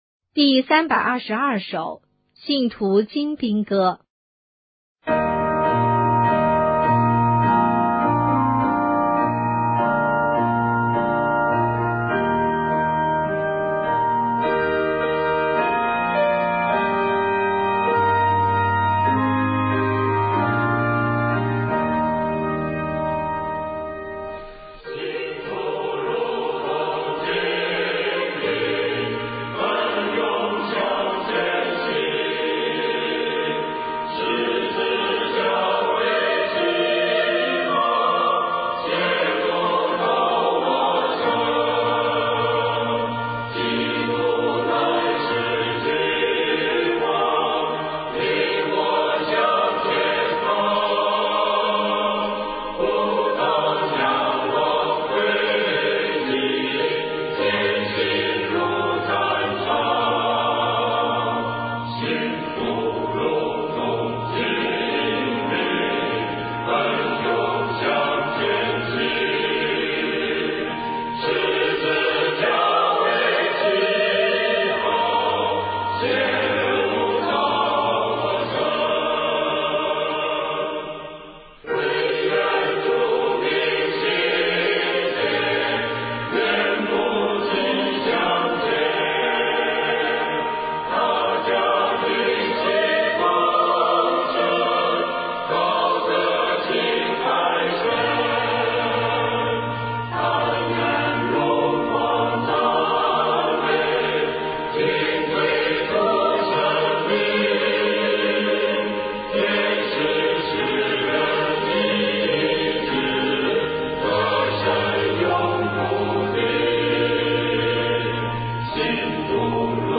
赞美诗 | 信徒精兵歌